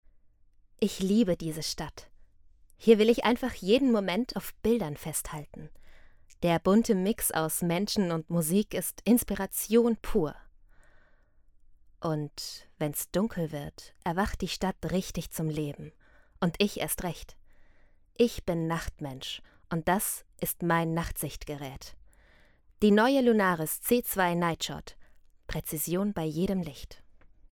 Warm, klar, einfühlsam – meine Stimme zieht Zuhörer*innen in ihren Bann, vermittelt Emotionen und hinterlässt Eindrücke, die bleiben.
Lunaris Werbetext